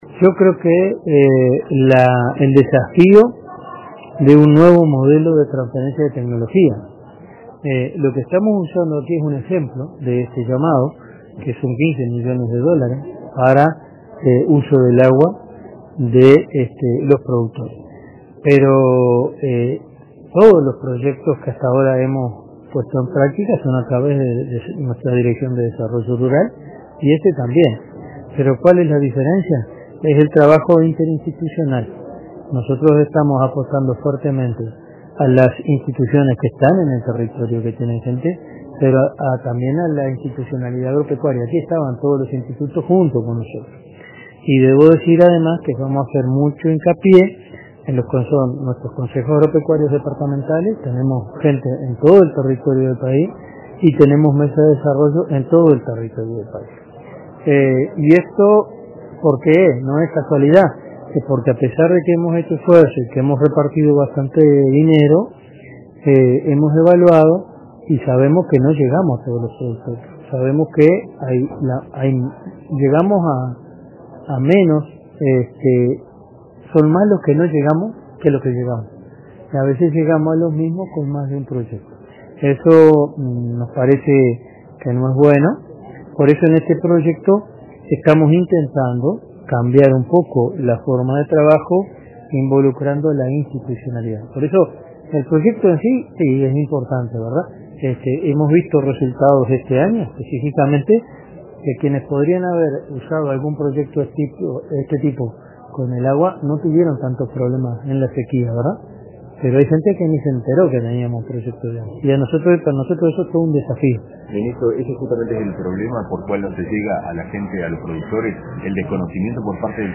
“La diferencia con proyectos anteriores es que estamos apostando fuertemente a la interinstitucionalidad, al trabajo con las instituciones en el territorio, pero también a la institucionalidad agropecuaria”, explicó el ministro Enzo Benech, en rueda de prensa, tras la presentación de la convocatoria realizada este miércoles 25 en la sede ministerial.